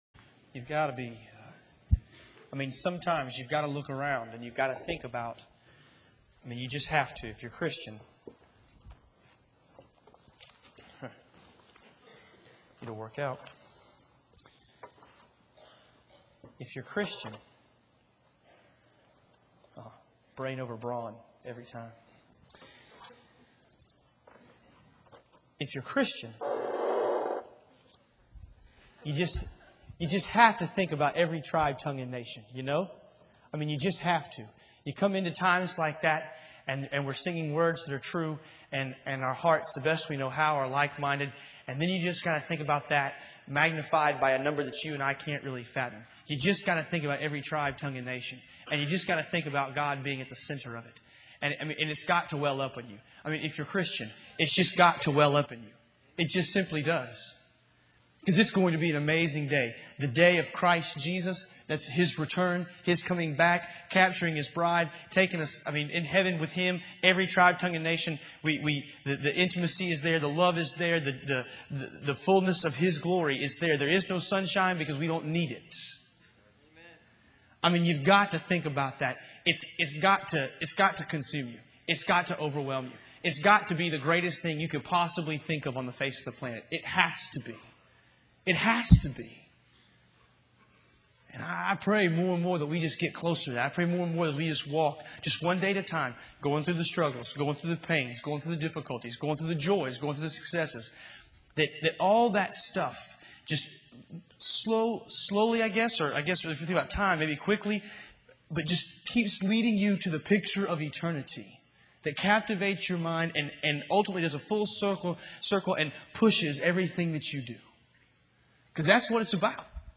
Union University, a Christian College in Tennessee